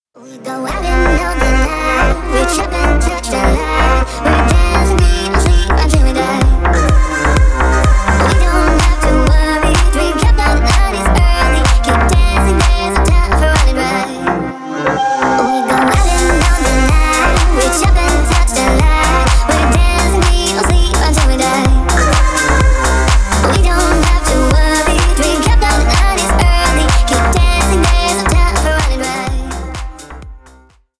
Электроника
клубные